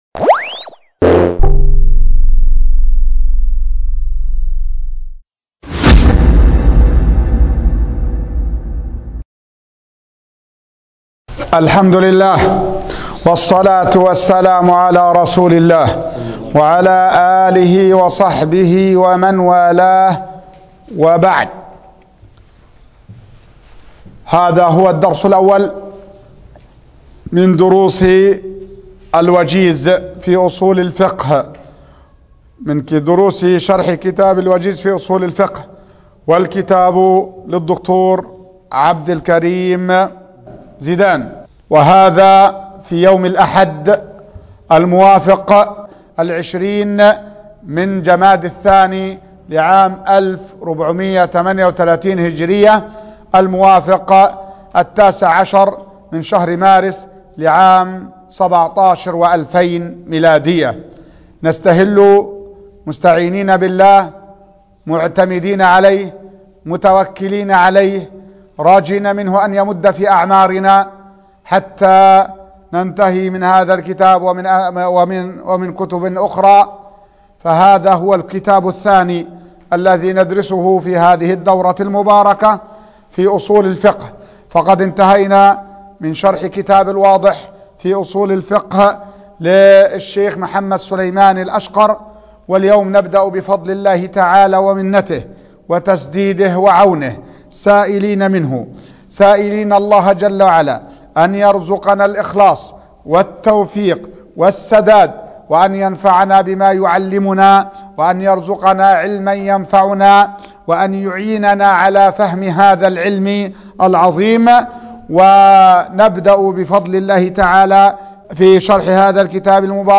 الدرس الاول -الوجيز في اصول الفقه